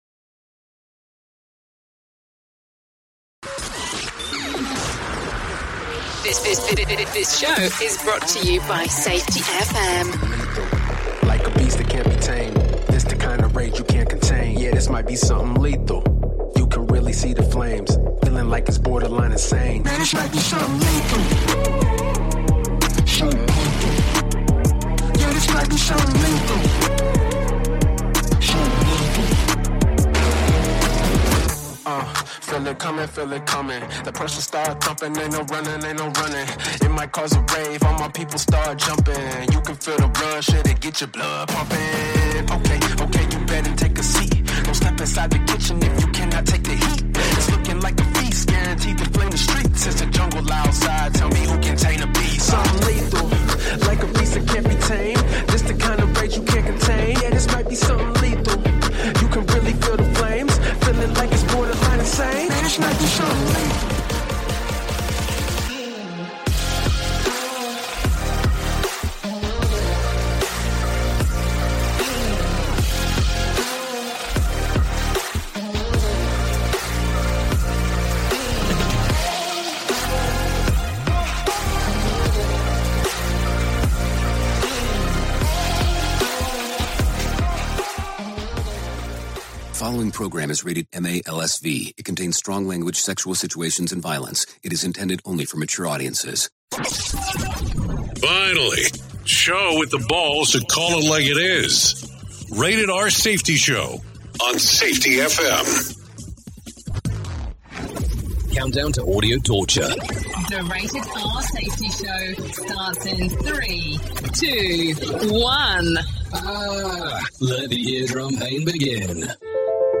Broadcasting from the Safety FM studios in Orlando, Florida, and streaming across the multiverse of madness that is Safety FM .